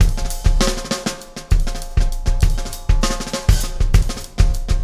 Extra Terrestrial Beat 03.wav